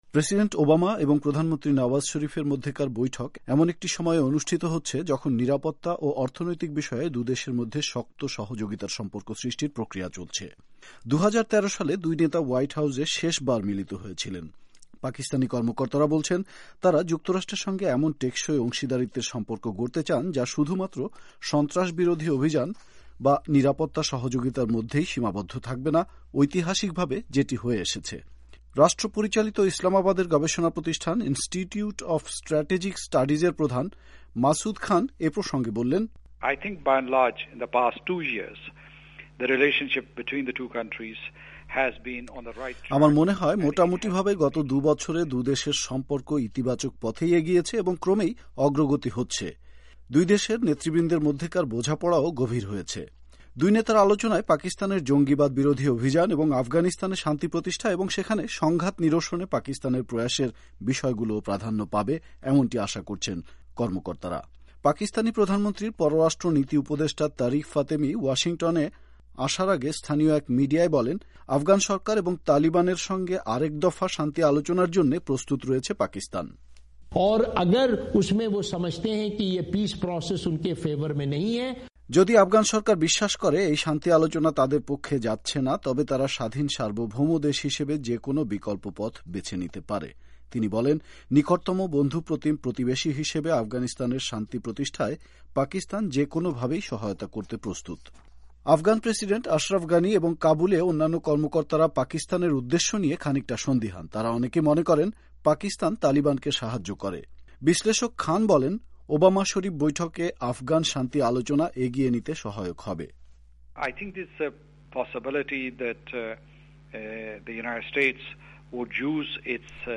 রিপোর্ট থেকে শোনাচ্ছেন